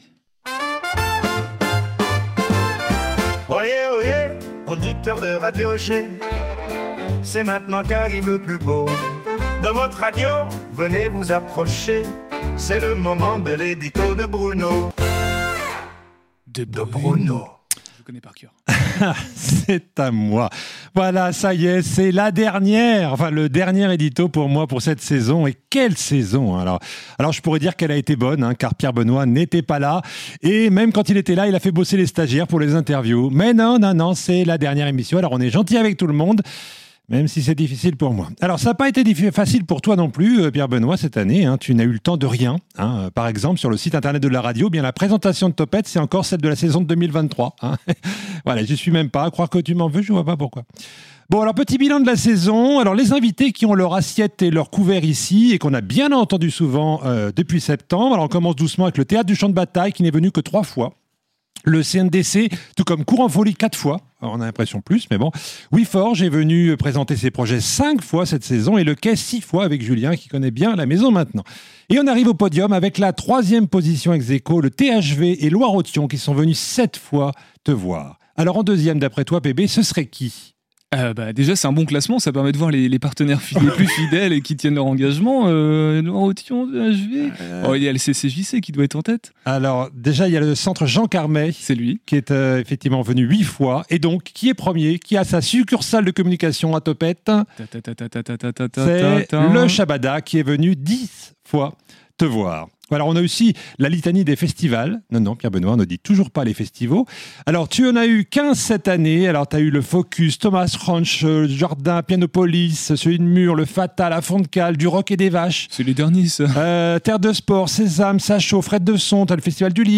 À chaque émission, il déclame son édito en se moquant de ses petits camaradesJeux de